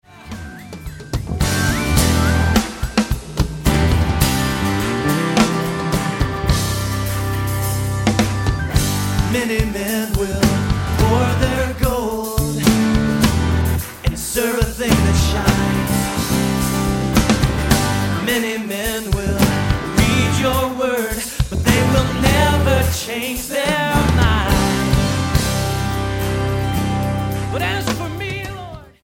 STYLE: MOR / Soft Pop
with enthusiastic praise & worship